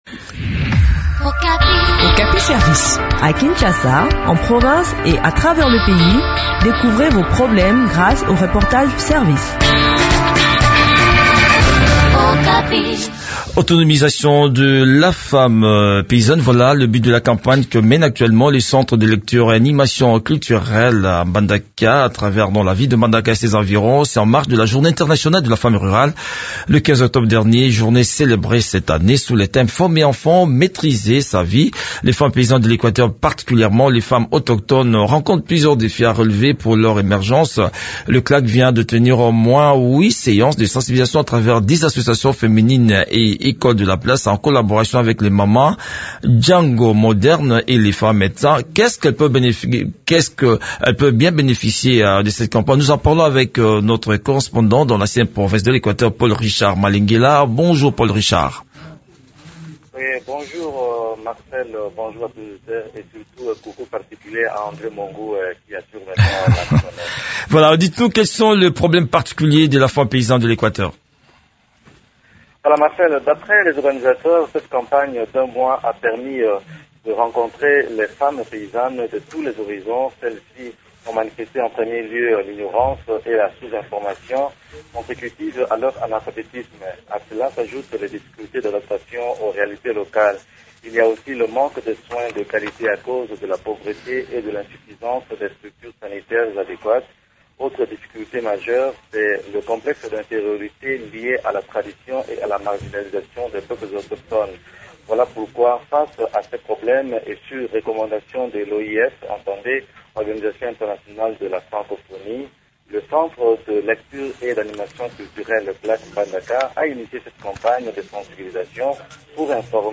Le point sur le déroulement de cette campagne dans cet entretien